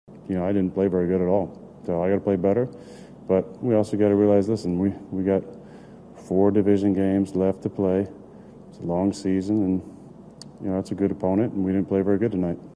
Rodgers said that he is not panicking yet.